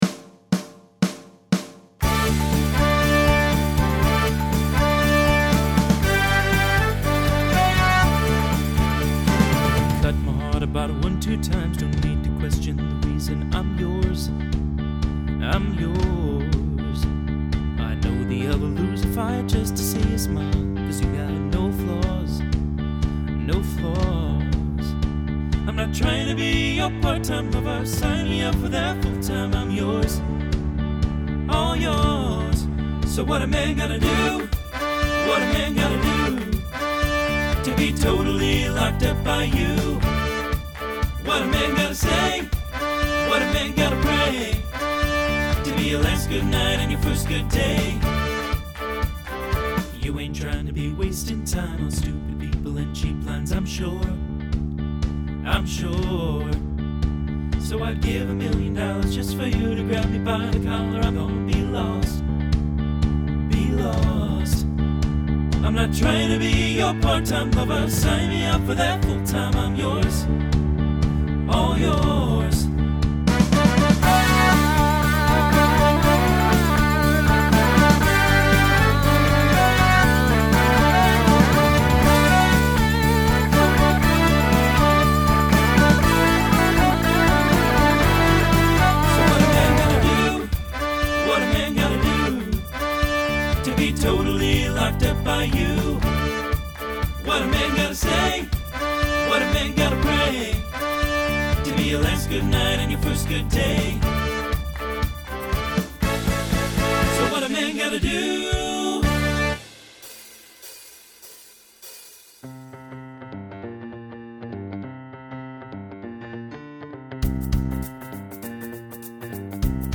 (TTB)
(SSA)
Genre Pop/Dance
Transition Voicing Mixed